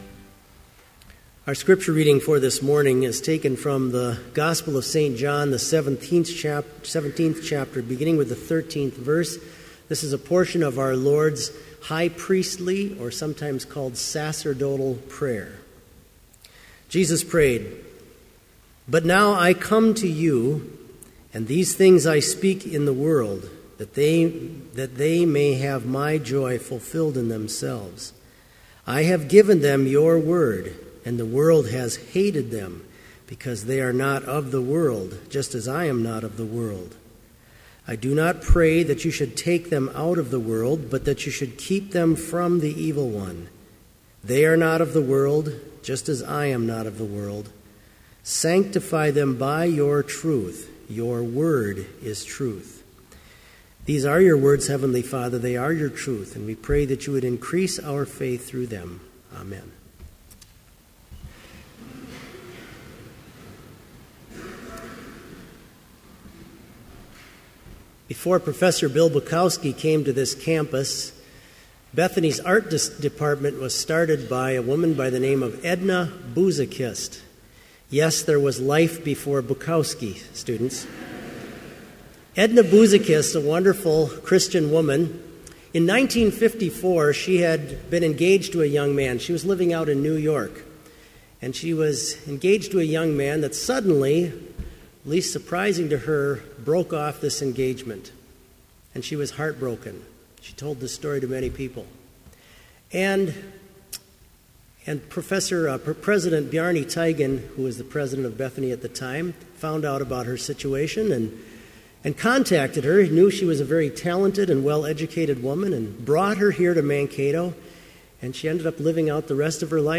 Complete service audio for Chapel - May 14, 2014